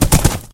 gallop4.mp3